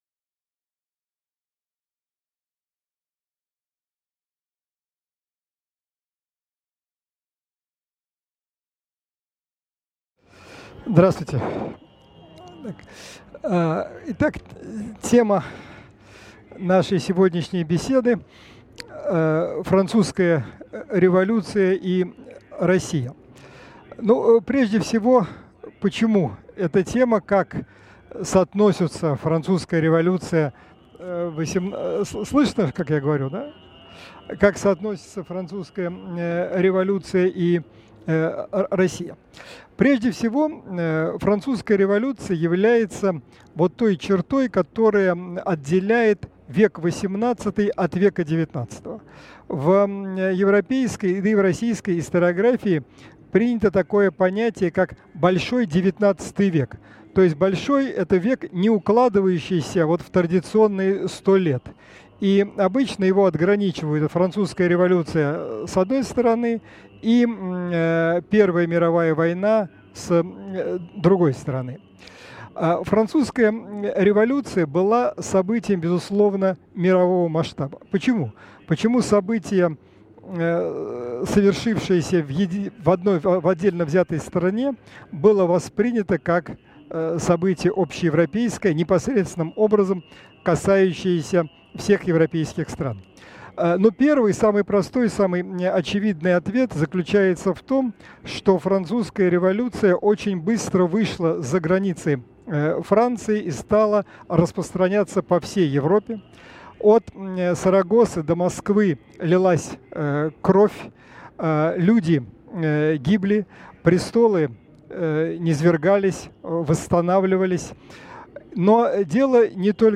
Аудиокнига Россия и Французская революция | Библиотека аудиокниг